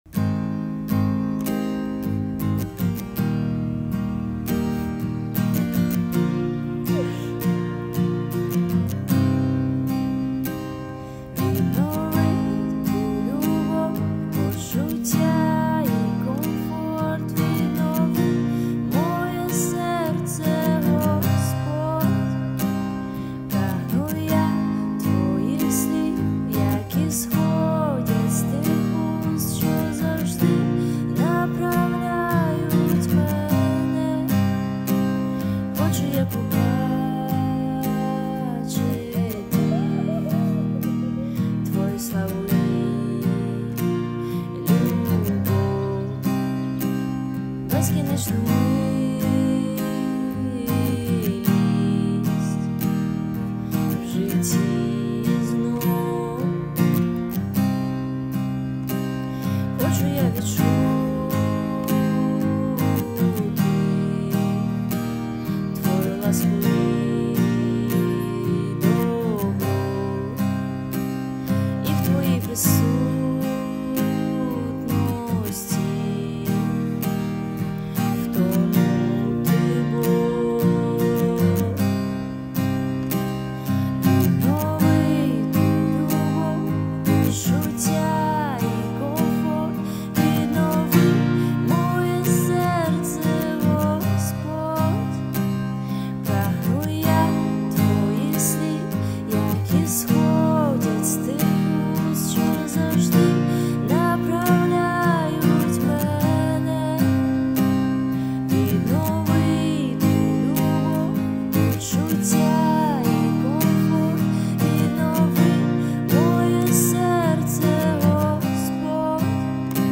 86 просмотров 65 прослушиваний 6 скачиваний BPM: 80